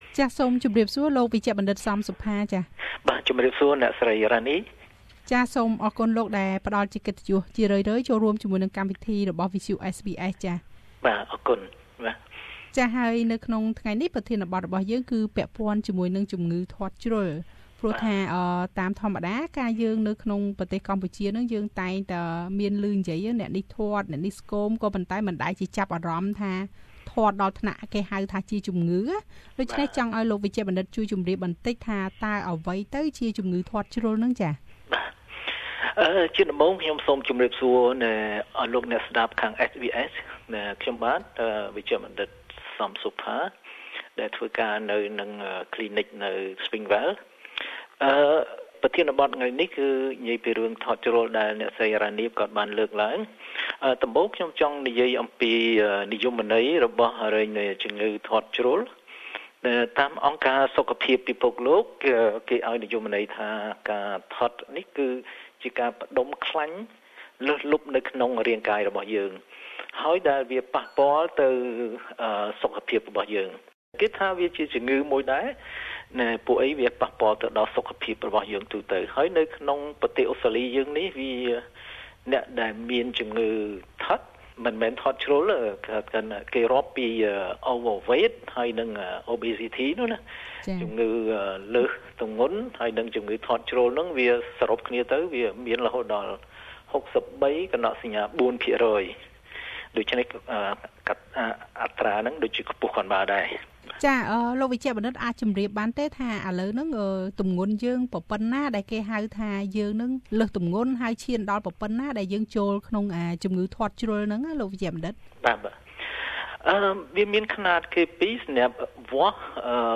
How do they measure it? Is it genetic? How can you control it? Please listen to an interview